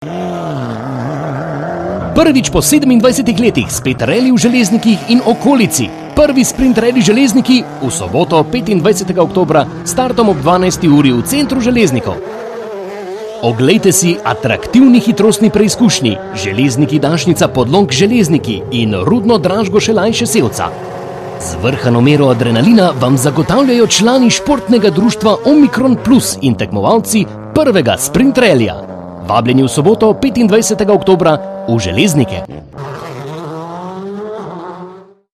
Lahko ga pogledate v naravni velikosti oz. dolpotegnete :) Reklama za 1. Sprint rally Naš medijski pokrovitelj Radio Sora je za naš dogodek pripravil udarno reklamo: Reklamo lahko prenesete tukaj